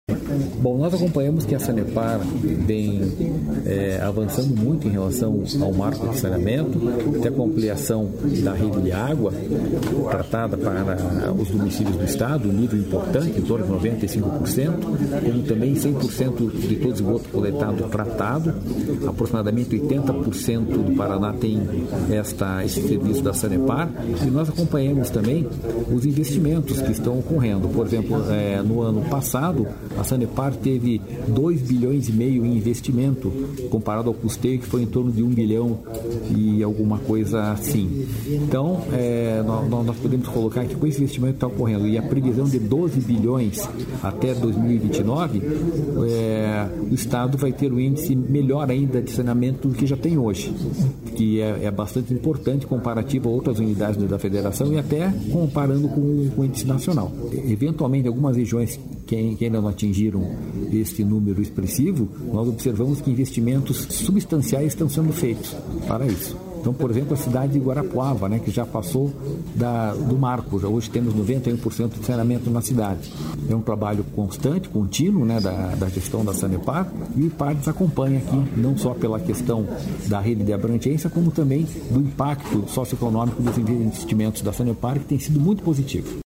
Sonora do presidente do Ipardes, Jorge Callado, sobre a nova Pesquisa por Amostra de Domicílios